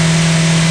1 channel
MOTOR5.mp3